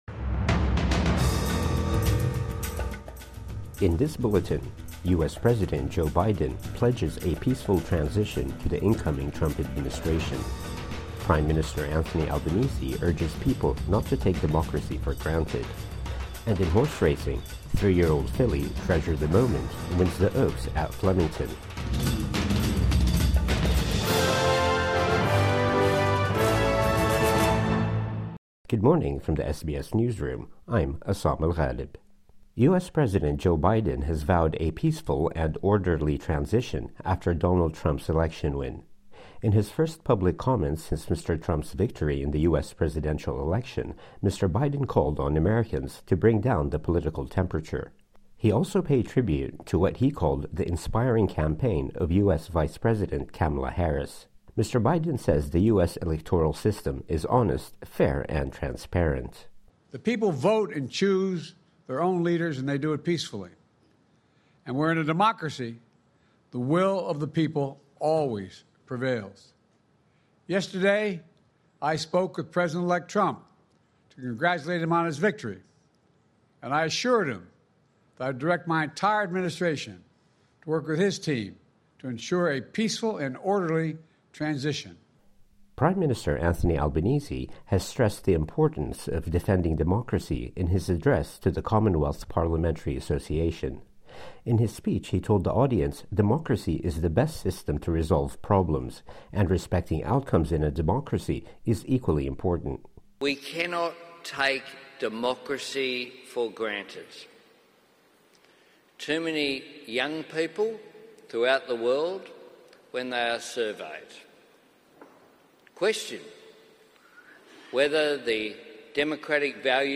Morning News Bulletin 8 November 2024